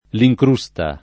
lincrusta [ li j kr 2S ta ] s. f.